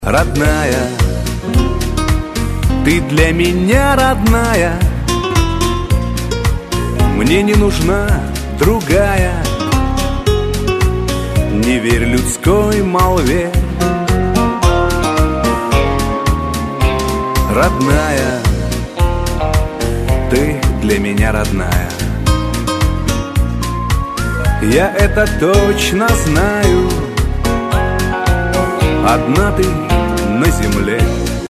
Категория: Шансон | Дата: 10.12.2012|